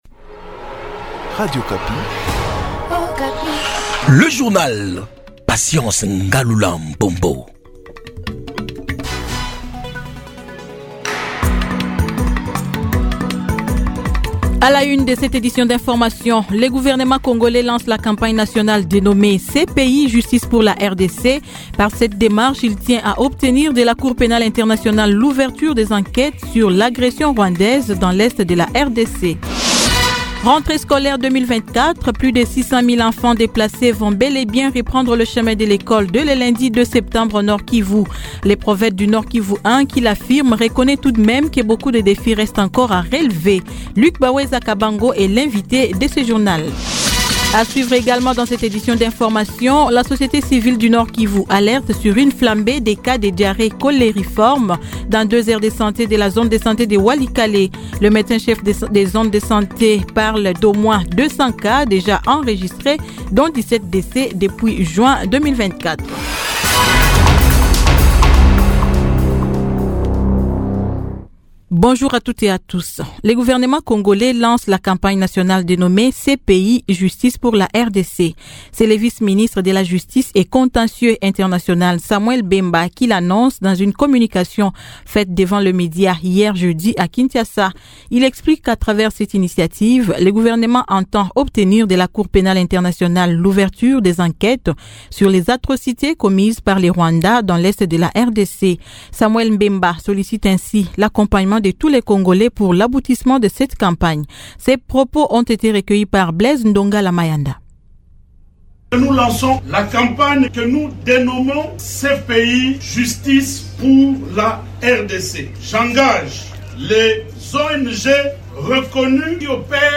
Journal matin 06H-07H
Kinshasa : Reportage sur la perception des frais de justice.